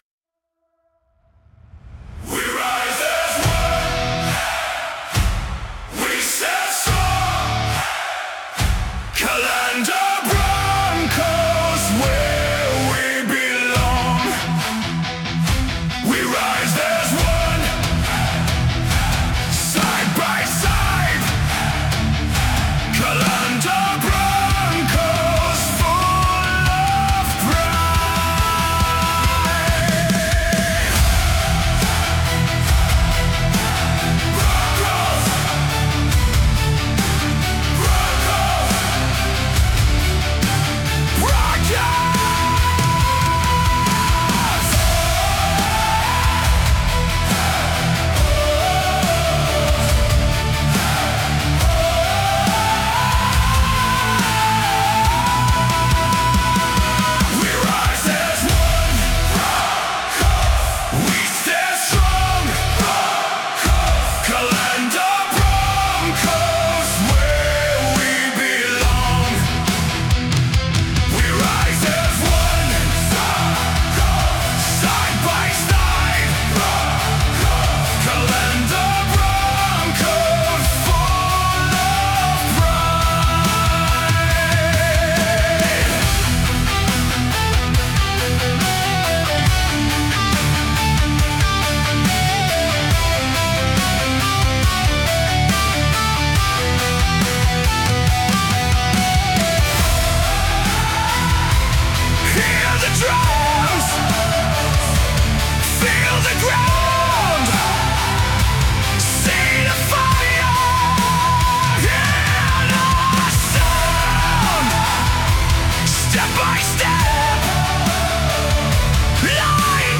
Ein kraftvolles Anthem